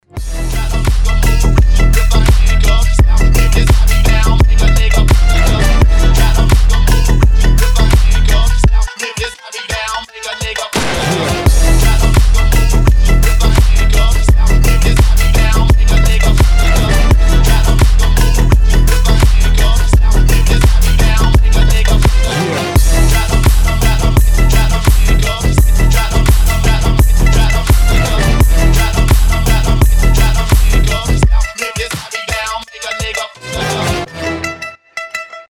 • Качество: 320, Stereo
мощные басы
качающие
G-House
фонк
Хаус и фонк в одной нарезке